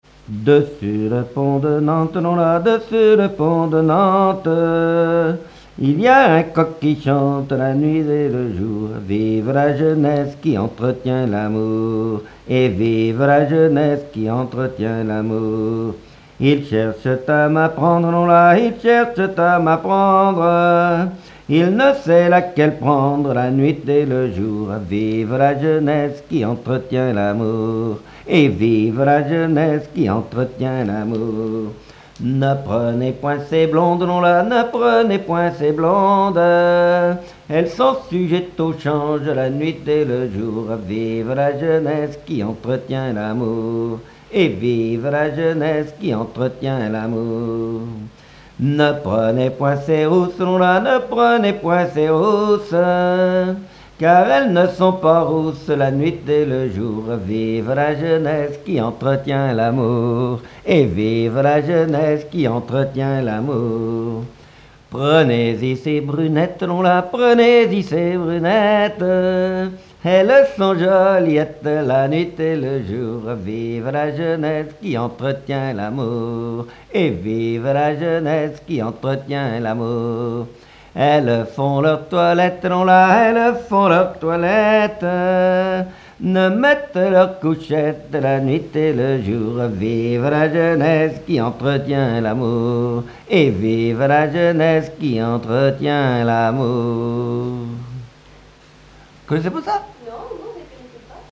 Chansons populaires et traditionnelles
Pièce musicale inédite